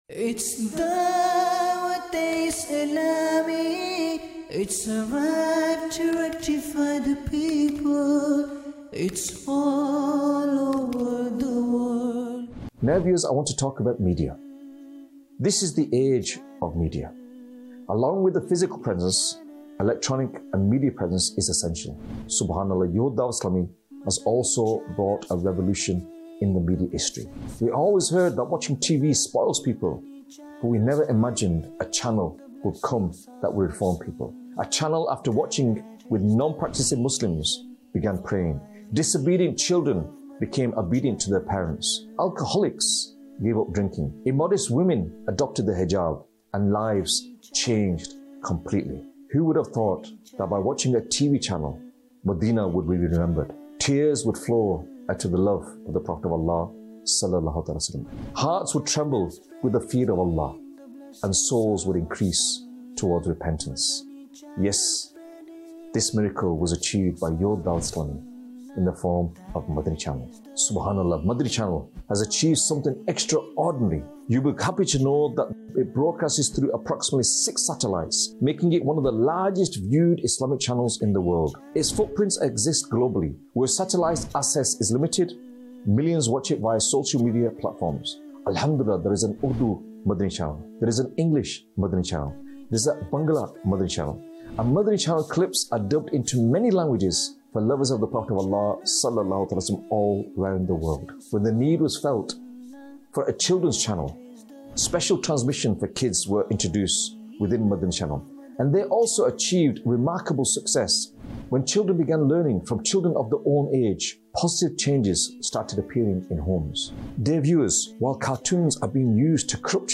khutba
Documentary 2026